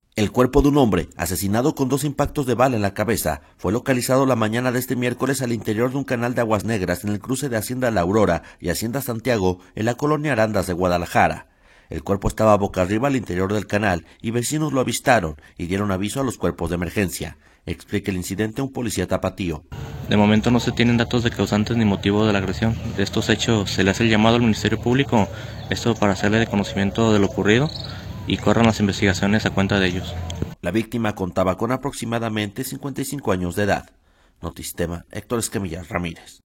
El cuerpo de un hombre asesinado con dos impactos de bala en la cabeza, fue localizado la mañana de este miércoles al interior de un canal de aguas negras en el cruce de Hacienda La Aurora y Hacienda Santiago en la colonia Arandas de Guadalajara. El cuerpo estaba bocarriba al interior del canal y vecinos lo avistaron y dieron aviso a los cuerpos de emergencia; explica el incidente un policías tapatío.